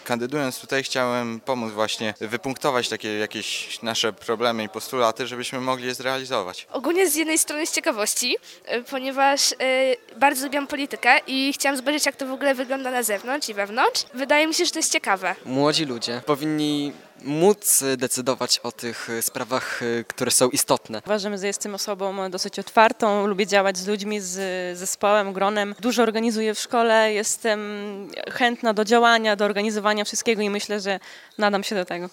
Młodych radnych reporter Radia 5 zapytał o powody, dla których zdecydowali się kandydować do Rady.